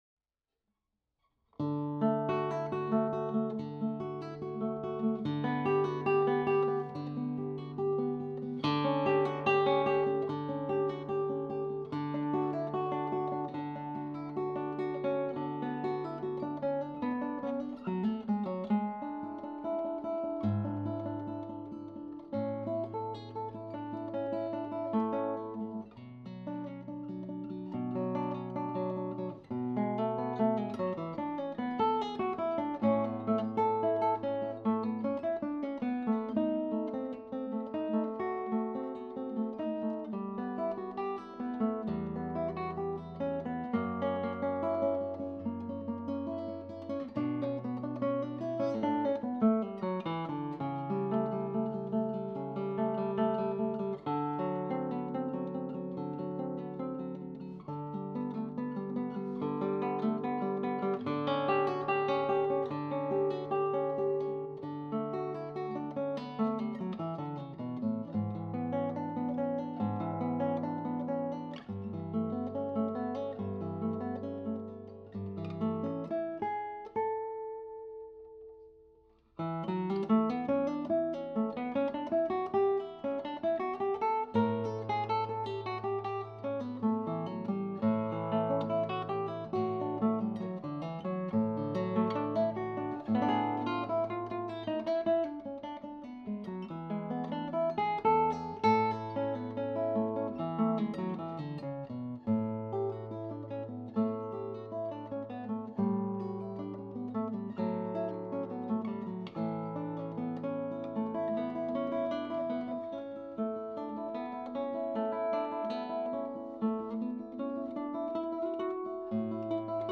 KU277 Rosewood and Spruce Classical Guitar
Lovely full tonal range Classical Guitar with a French Polish finish and Savarez Cristal Soliste 570CR strings.